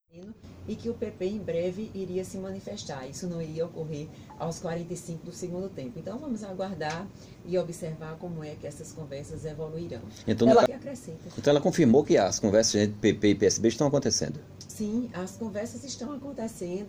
A ex-secretária e esposa do deputado federal, Veneziano Vital do Rêgo (PSB), Ana Claudia Nóbrega Vital do Rêgo (Podemos), disse hoje no Correio da Manhã da 98 FM de Campina Grande, que existe uma conversa entre PP e PSB, no sentido de uma composição com a candidatura de João Azevedo para o governo do estado.